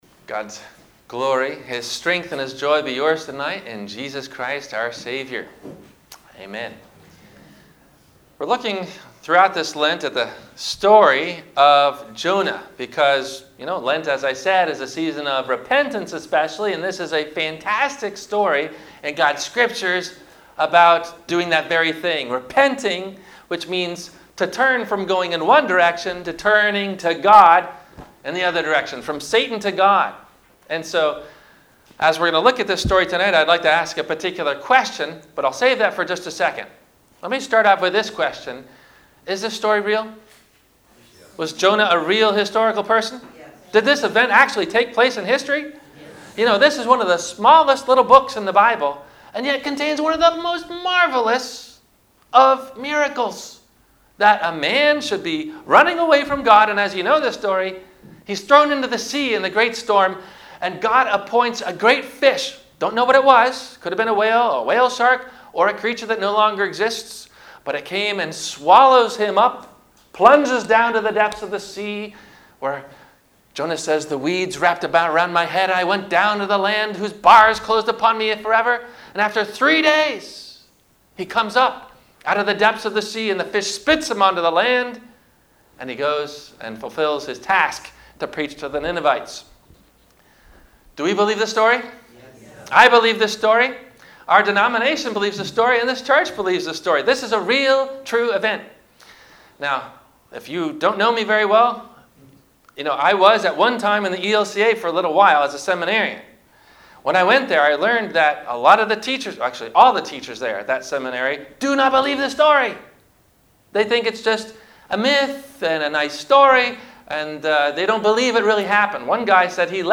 - Ash Wednesday - Lent 1 - Sermon - March 6 2019 - Christ Lutheran Cape Canaveral